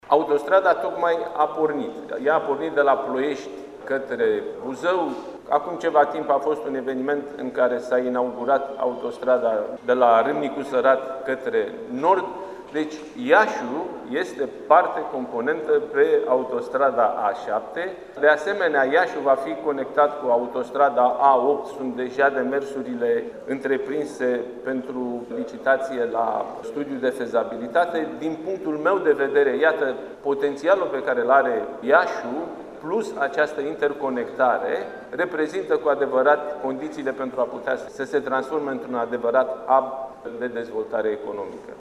Cea mai mare clădire de birouri din România, Palas Campus, a fost inaugurată astăzi, la Iaşi, în prezenţa premierului Nicolae Ciucă şi a ambasadorului SUA la Bucureşti, Kathleen Kavalec.